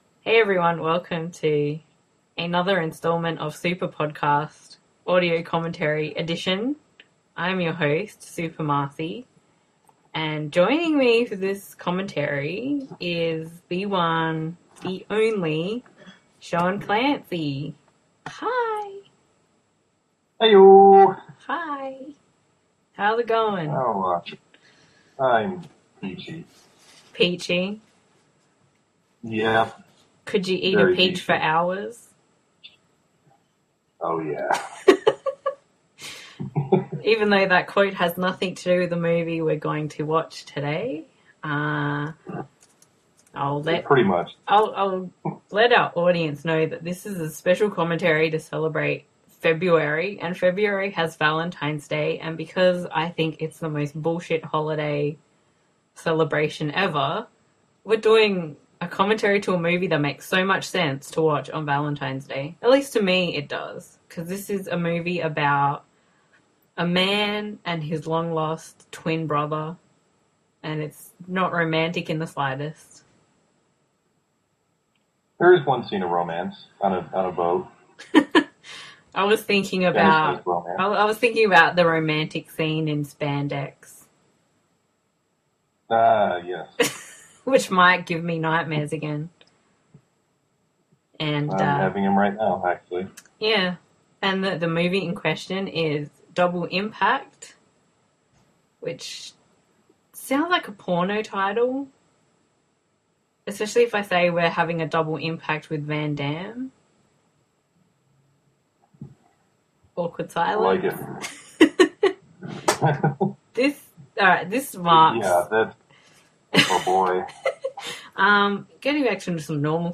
You simply need to grab a copy of the film, and sync up the podcast audio with the film.
Disclaimer: This audio commentary isn’t meant to be taken seriously, it is just a humourous look at an action classic film.